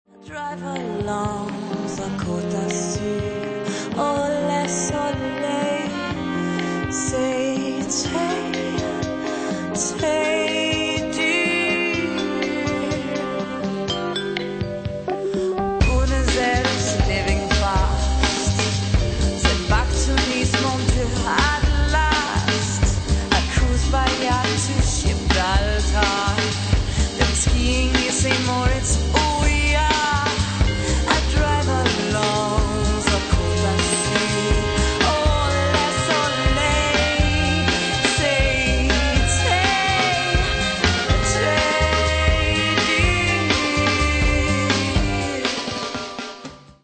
Lounge-Jazz-Combo